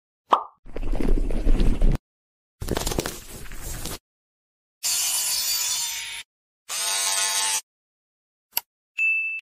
ASMR 2d relaxing sounds 🤯 sound effects free download